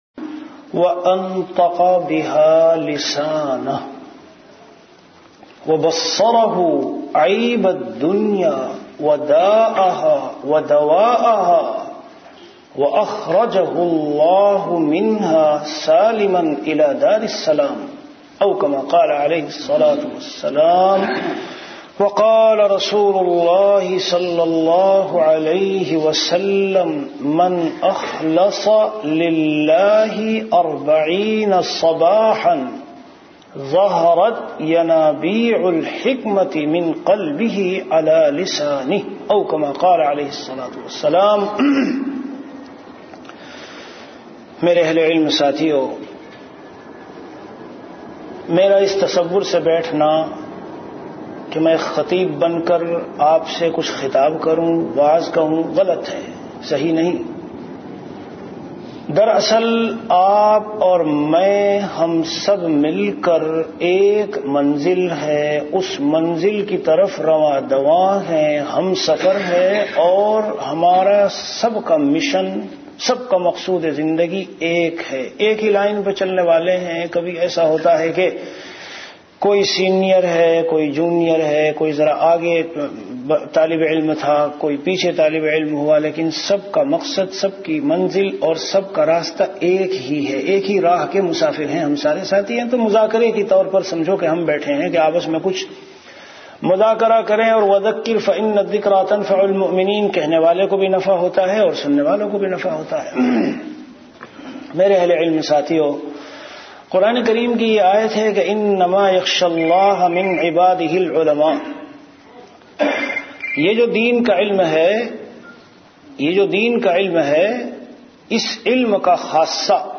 Delivered at Qatar.